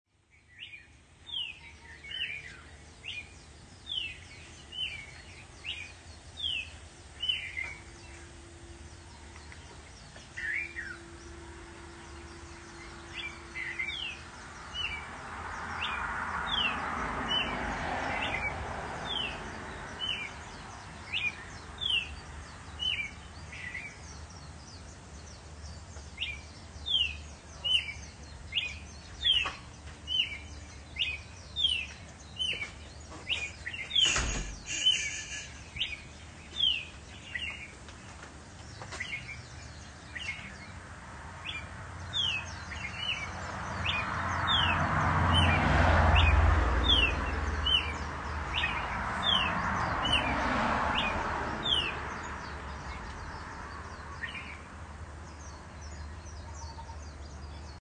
A happy cardinal has been serenading us in the early mornings
For several days, one of the red-crested birds known as Brazilian cardinals, Southern cardinals, or simply red crested cardinals, has been up a half-hour or so before sunrise chirping loudly from a perch high in a tree out in front of our yard.
Occasionally they’ll be an answering call from somewhere “off stage.”
I thought you might enjoy this little recording made on a recent morning with my iPhone. I apologize for not taking the time to edit out several passing cars and other sounds.